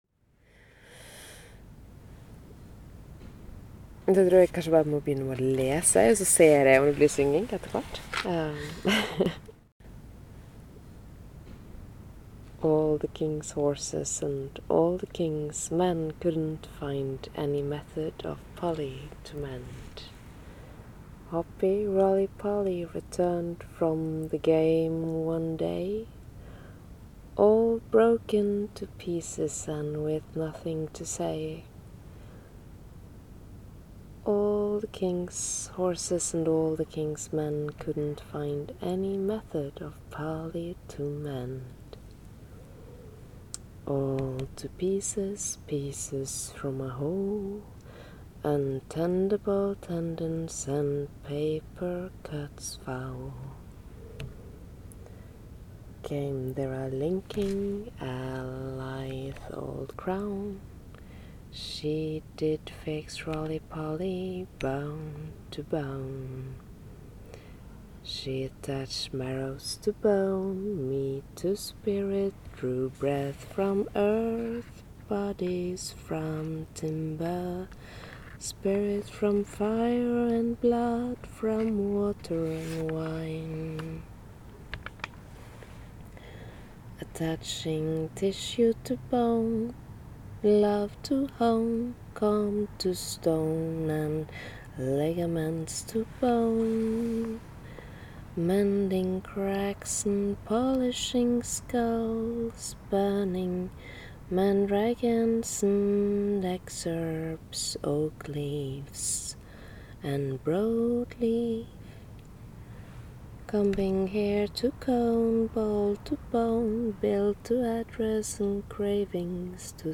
This is a live performance of an excerpt from Pink noise lullaby. At a bench below a lamp in the park at 01:13 CEST.